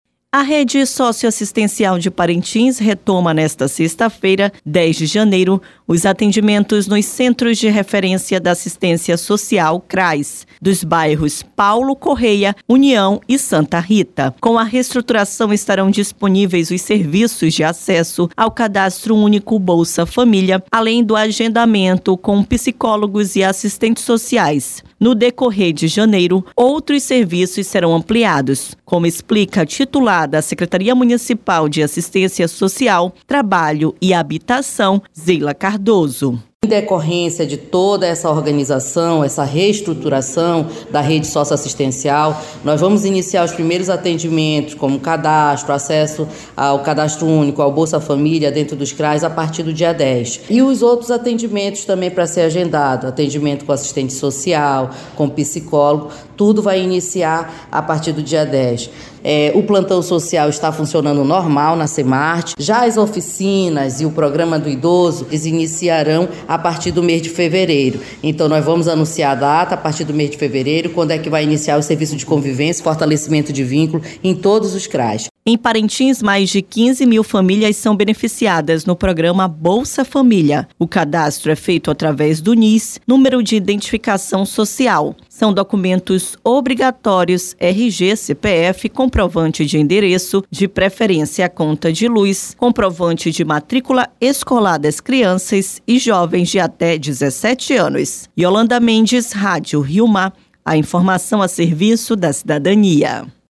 No decorrer de janeiro, outros serviços serão ampliados, como explica a titular da Secretaria Municipal de Assistência Social, Trabalho e Habitação, Zeila Cardoso.